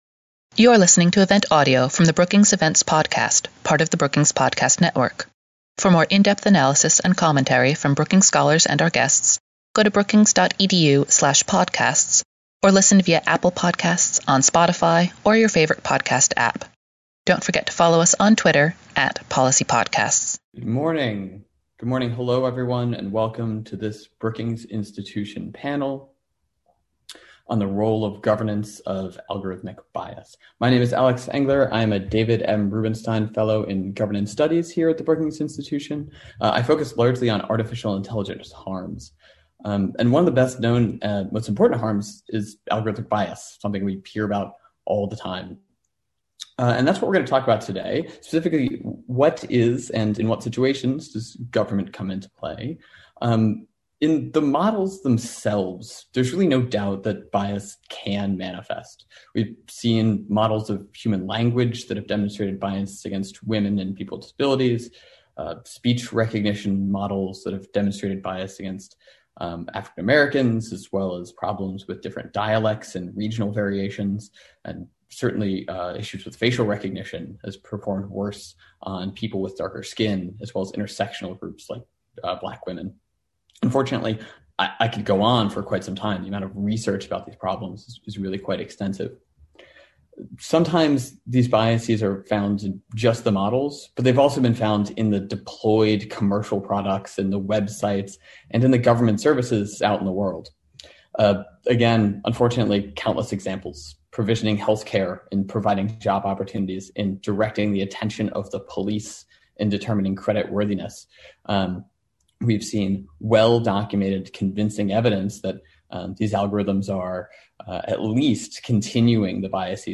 On March 12, the Center for Technology Innovation hosted a panel discussion on how governments can address the problem of bias in algorithms.